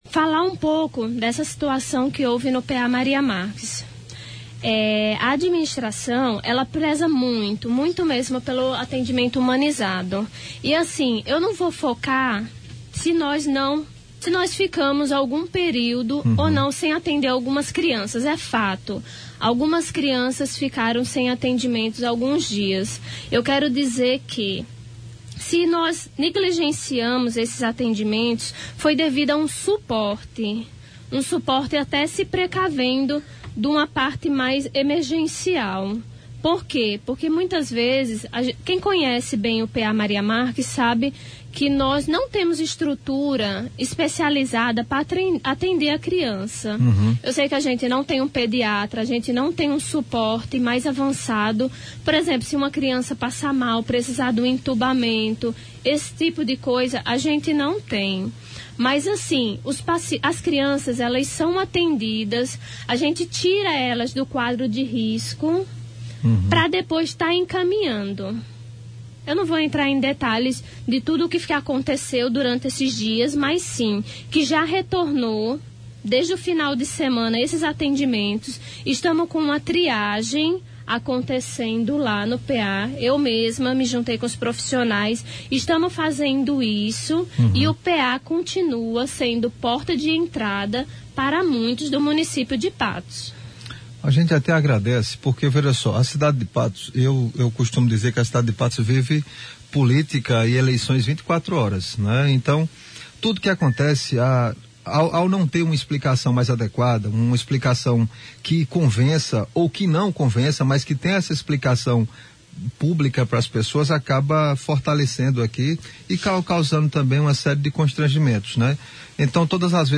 Durante cerca de 45 minutos, a diretora respondeu ao apresentador do programa, bem como aos ouvintes que participaram, utilizando os telefones e redes sociais.